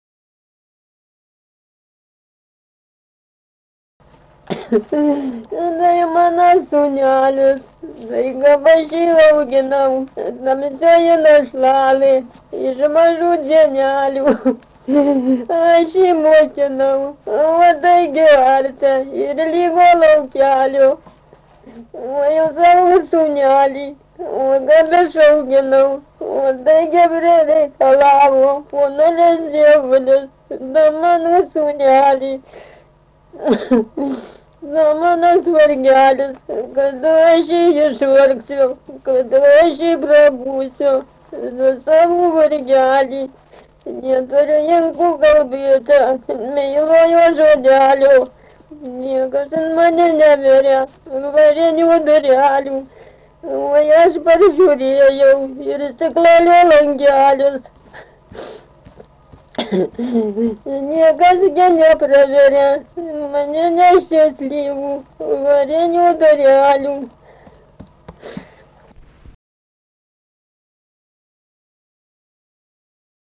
rauda
Pamerkiai
vokalinis